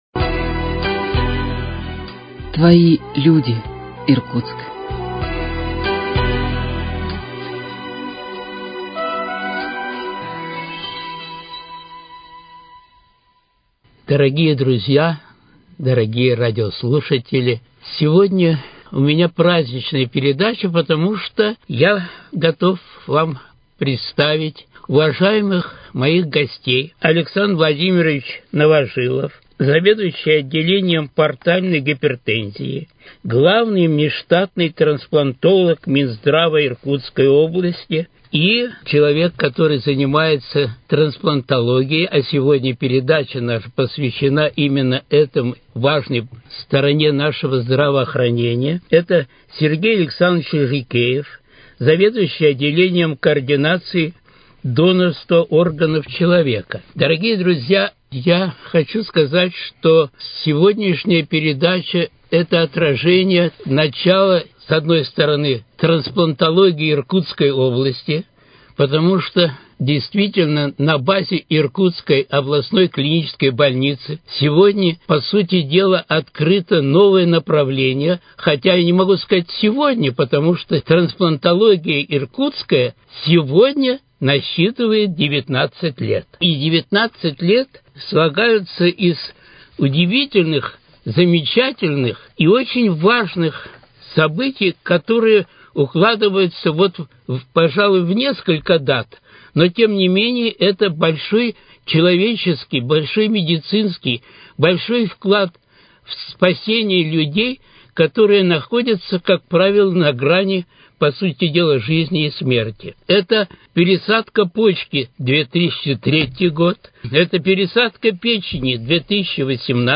Передача № 38 - В этом выпуске беседа об актуальном направлении медицины – трансплантологии.